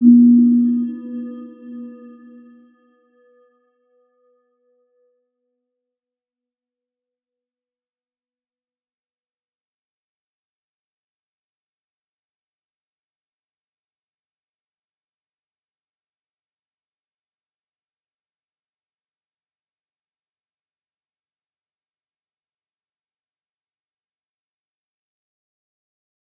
Round-Bell-B3-mf.wav